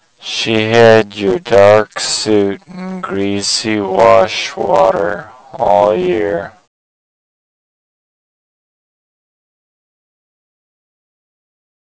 Warped sound with 66% Overlap